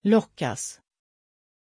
Aussprache von Loukas
pronunciation-loukas-sv.mp3